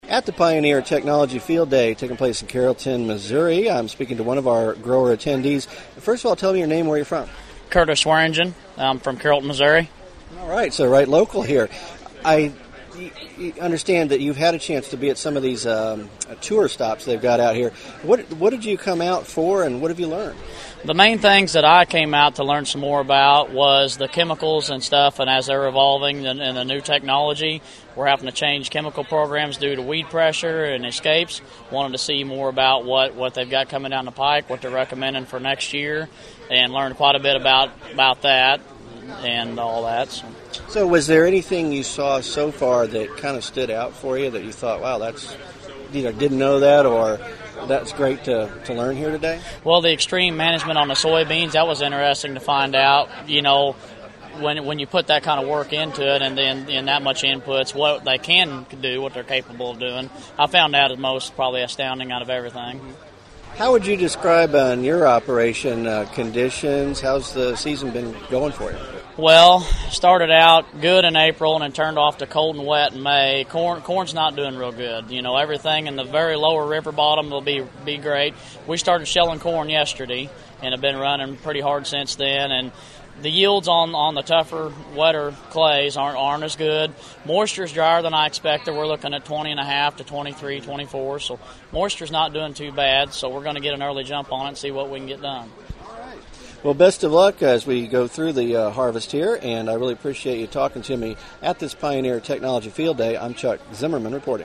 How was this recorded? Corn and Soybean Growers At Pioneer Field Day